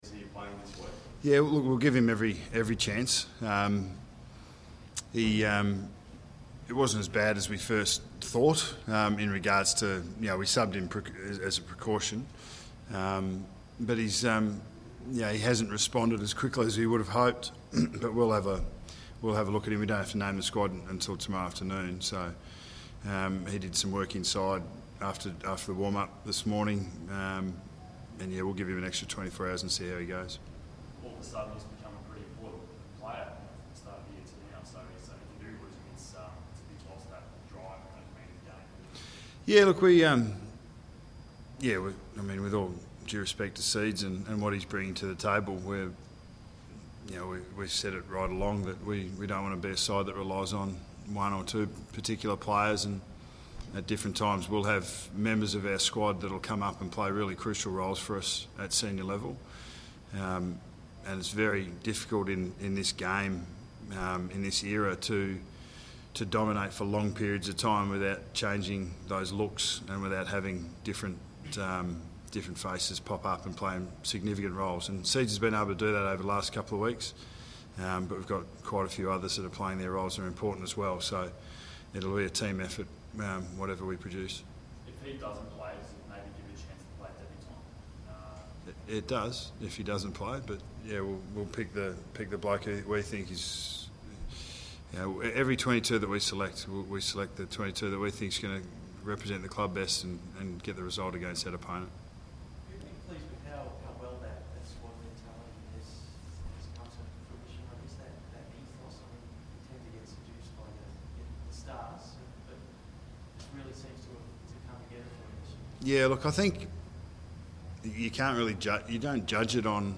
Press Conference: Nathan Buckley
Listen to coach Nathan Buckley as he fronts the media on Wednesday 6 May 2015 ahead of Collingwood's clash with Geelong.